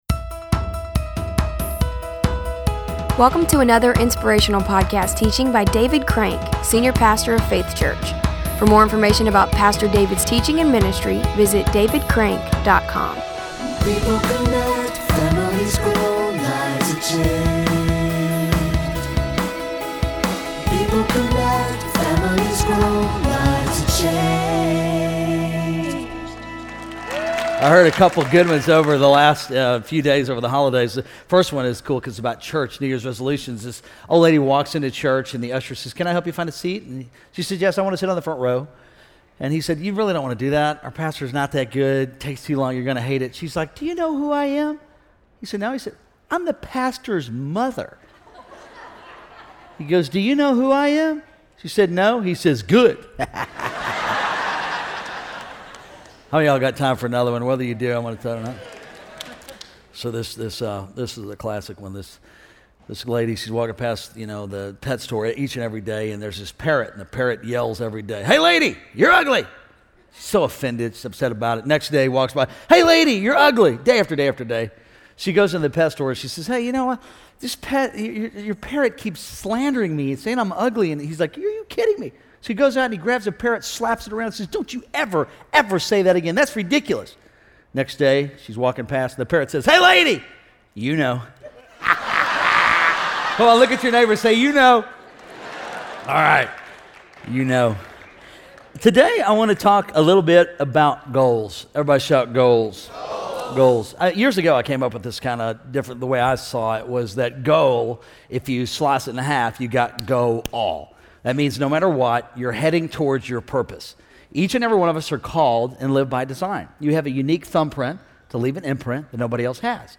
public ios_share Faith Church Audio Podcast chevron_right Goals Jan 7, 2015 30:32 forum Ask episode play_arrow Play view_agenda Chapters auto_awesome Transcript info_circle Episode notes Do you find yourself setting goals that you never get around to? In this sermon